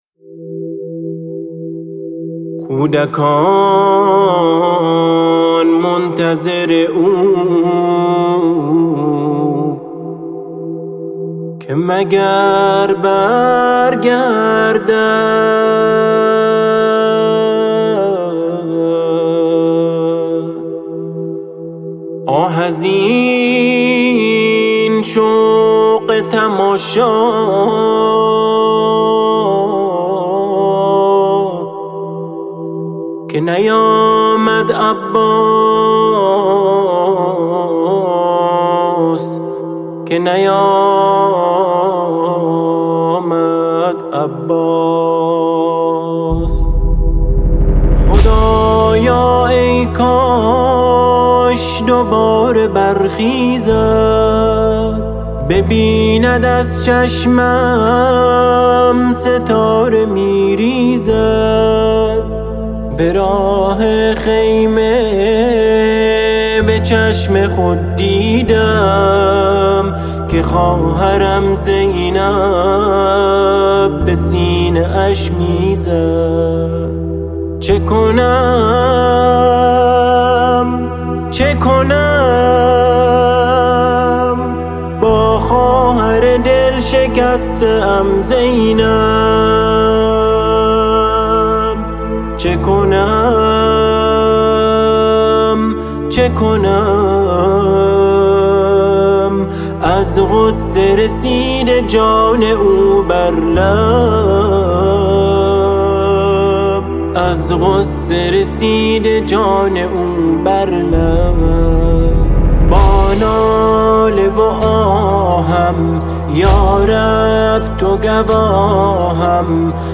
الله ویاک (خدا به همرات) مداح :حسین الاکرف
نوحه